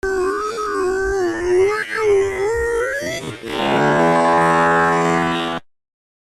Brain Fart Slowed